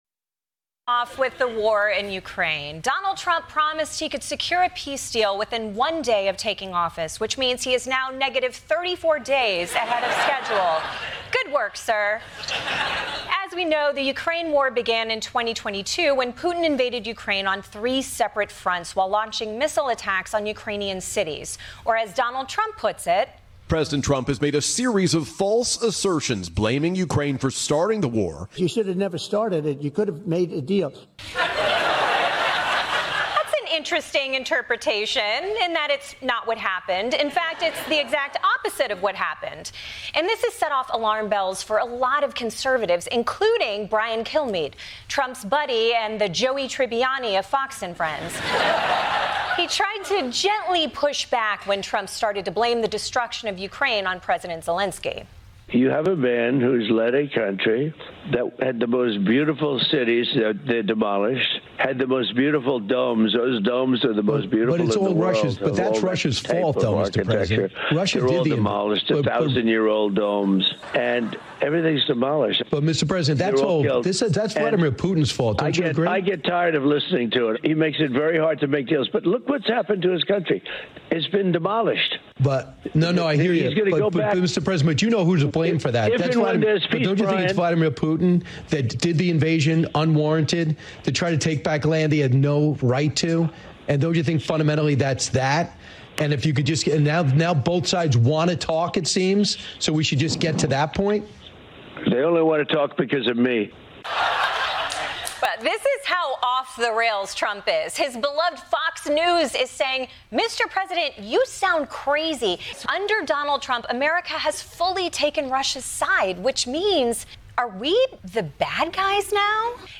We listen to a clip from Alina Habba talking more about Trump's reduction (destruction) of the government, a violation of the separation of powers.